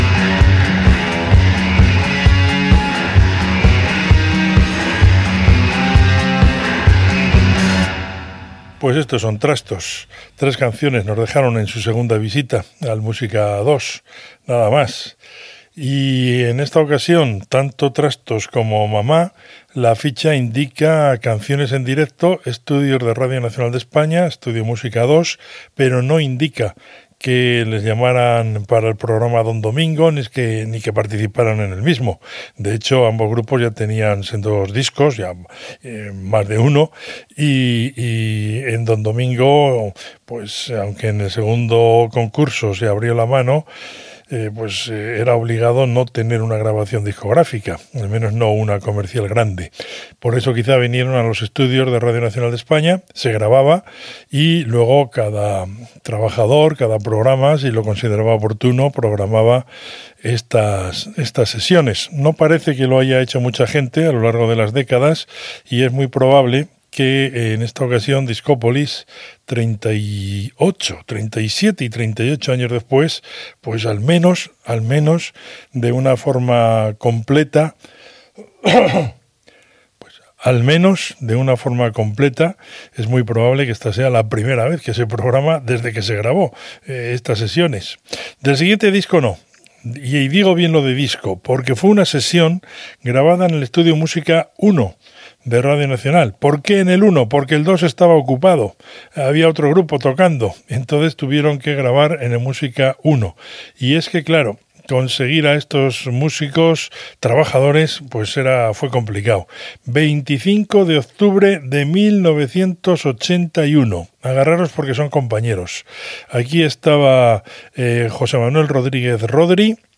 Tema musical interpretat en directe a l'Estudio 1 de Prado del Rey de Madrid
Musical